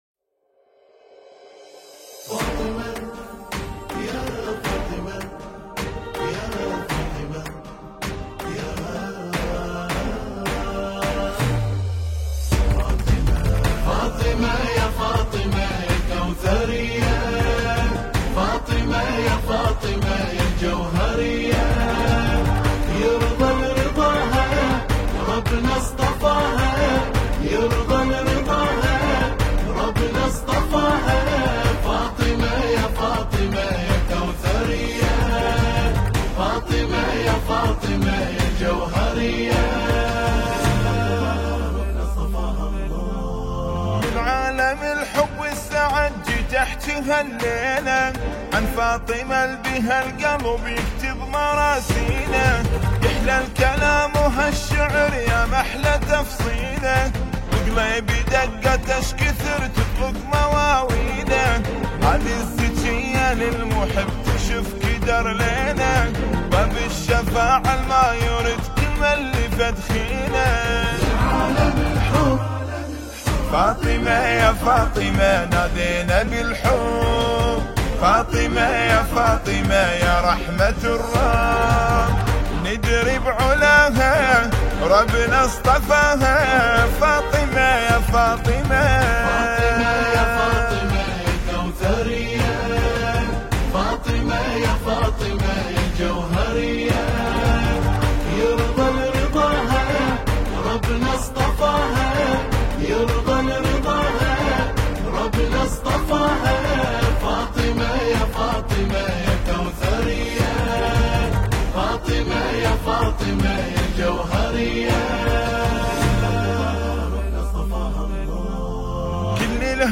الهندسة الصوتية و التوزيع